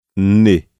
Maneira de articulação: nasal (abaixamento do véu palatino para que o ar que vem dos pulmões saia pela cavidade nasal)
Lugar de articulação: dental (ápice da língua e dentes superiores) ou alveolar (ápice da língua e alvéolos).
Som: [n]
Estado da glote: vozeado (há vibração das pregas vocais).